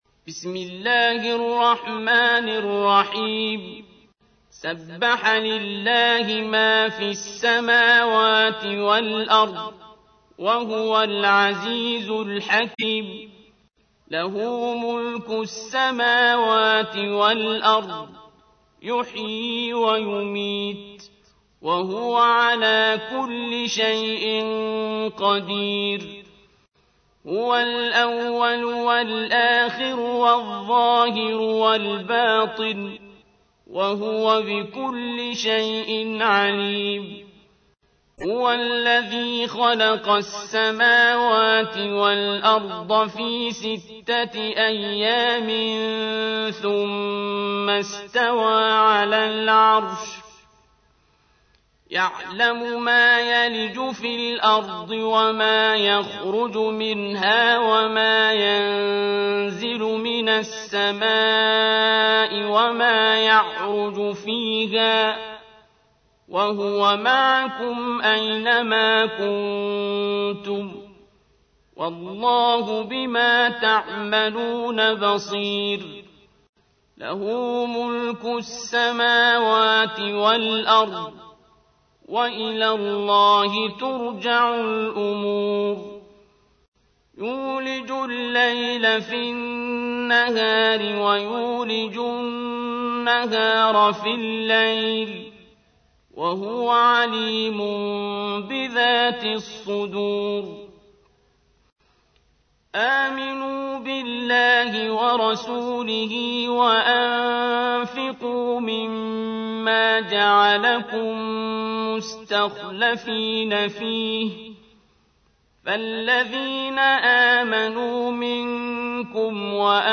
تحميل : 57. سورة الحديد / القارئ عبد الباسط عبد الصمد / القرآن الكريم / موقع يا حسين